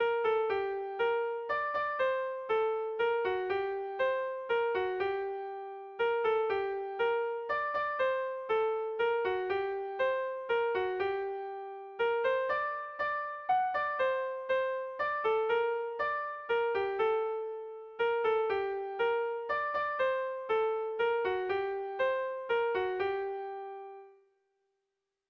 Bertso melodies - View details   To know more about this section
Sentimenduzkoa
Zortziko ertaina (hg) / Lau puntuko ertaina (ip)
AABA